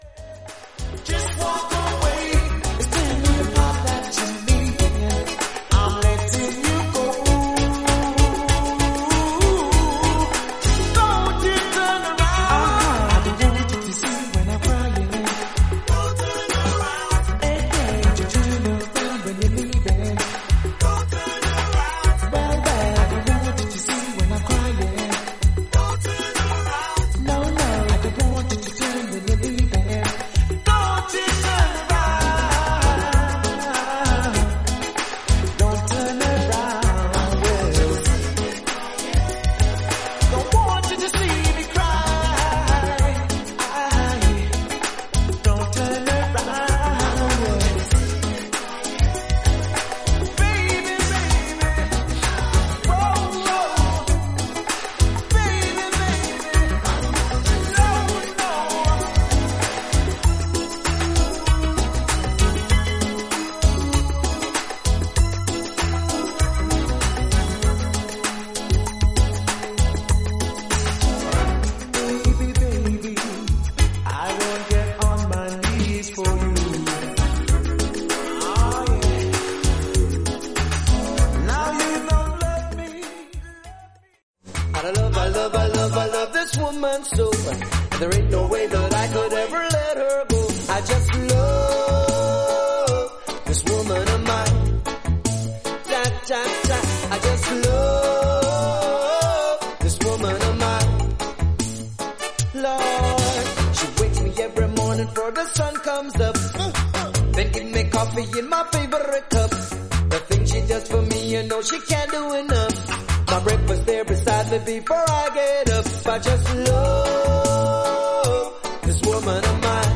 レゲエ・グループ